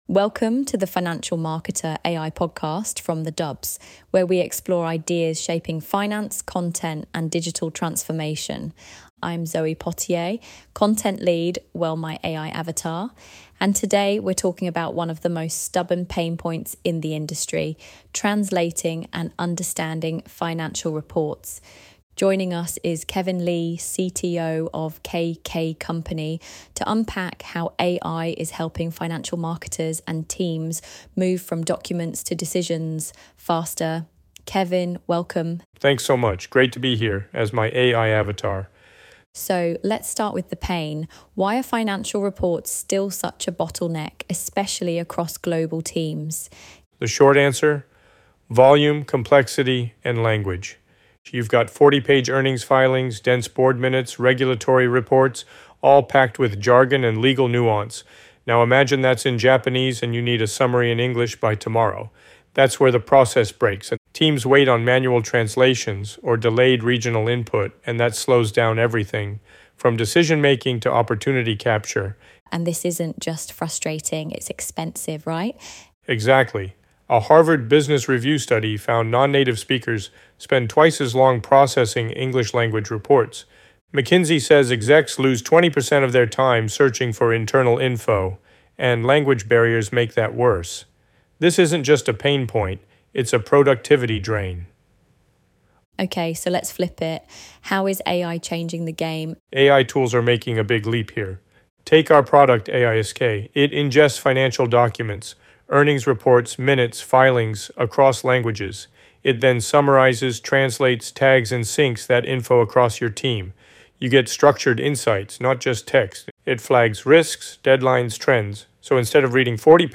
ElevenLabs_Breaking_Down_Language_Barriers_in_Global_Finance-3.mp3